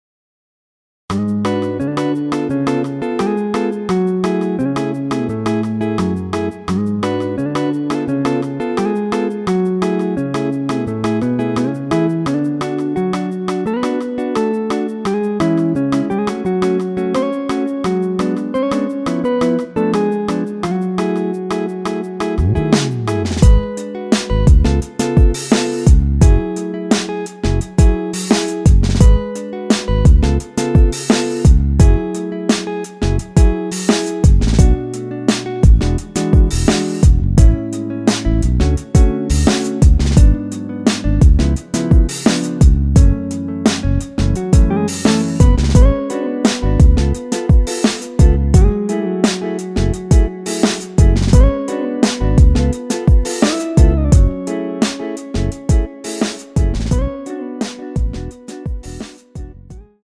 원키에서(-1)내린 MR입니다.
F#m
앞부분30초, 뒷부분30초씩 편집해서 올려 드리고 있습니다.
중간에 음이 끈어지고 다시 나오는 이유는